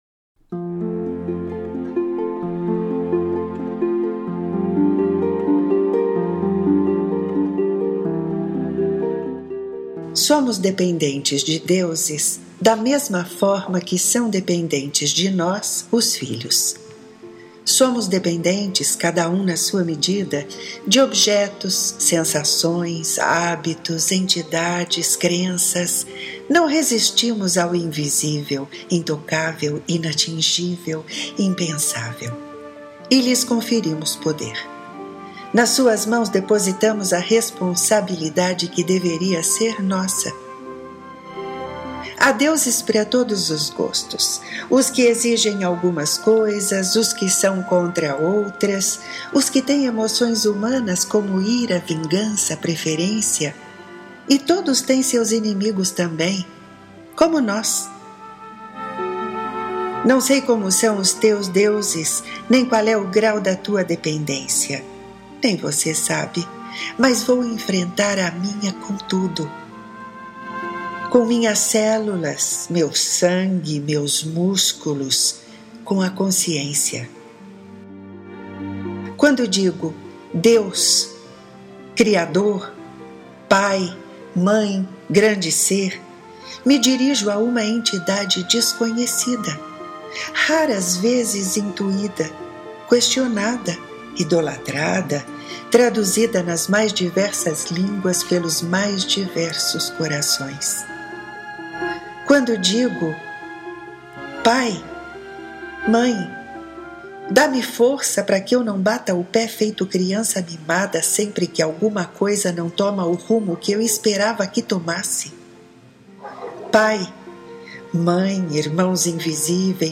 Ouça “De pai e mãe” na voz e sonorizado pela autora